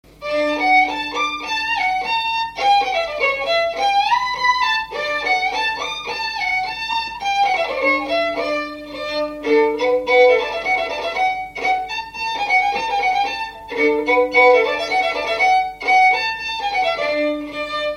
Résumé instrumental
danse : quadrille : chaîne anglaise
Pièce musicale inédite